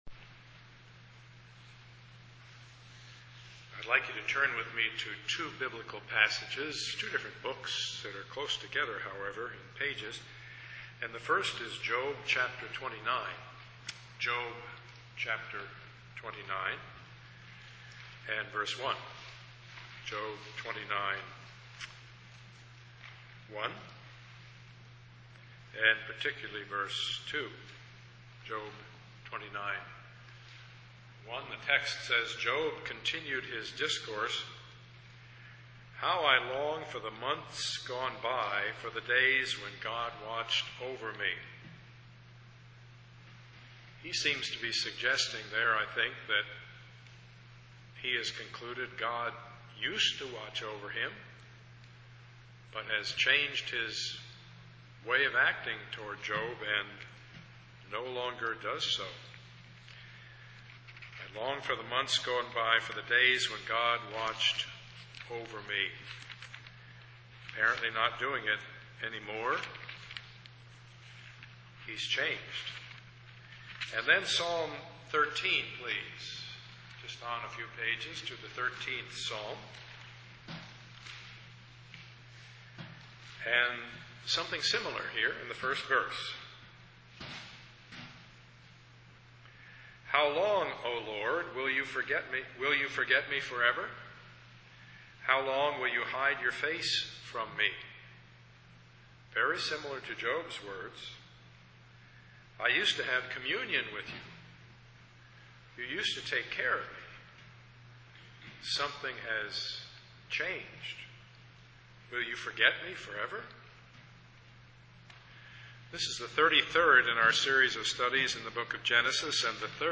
Genesis 18:1-33 Part 33 of the Sermon Series Topics: God's Decree « Does God Change His Mind?